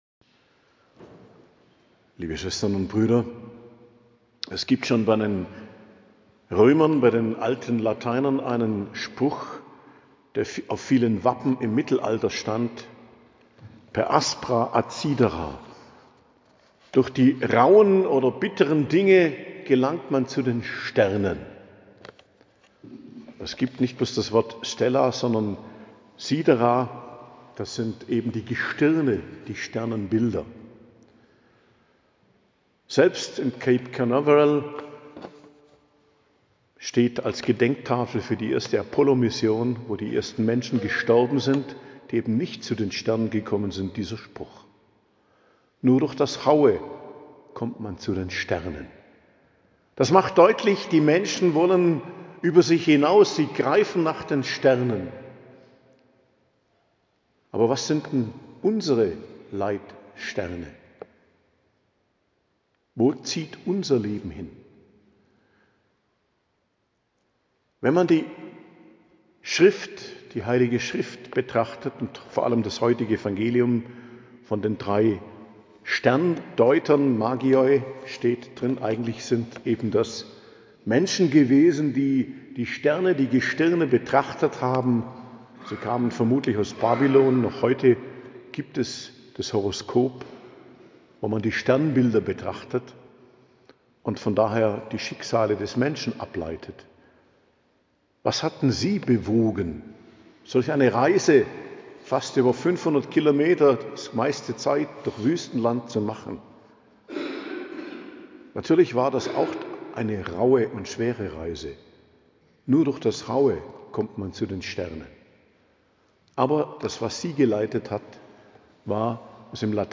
Predigt zum Hochfest Epiphanie - Erscheinung des Herrn, 6.01.2025